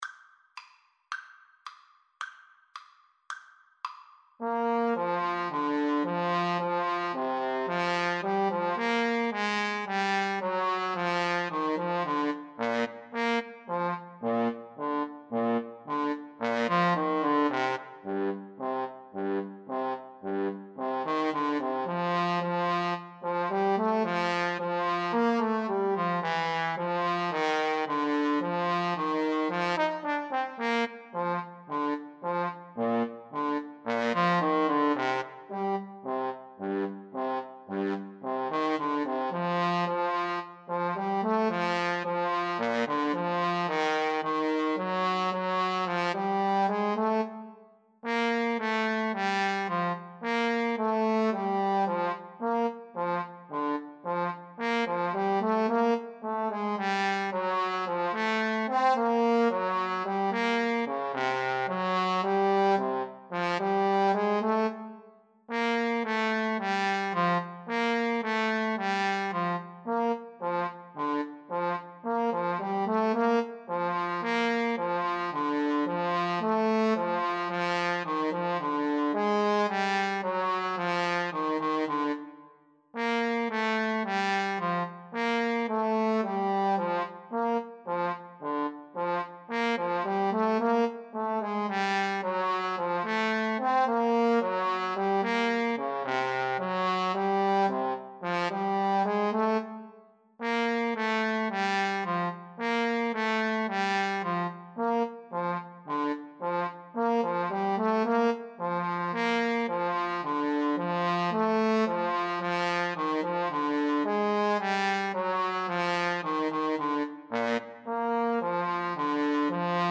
Moderato allegro =110
Classical (View more Classical Trombone Duet Music)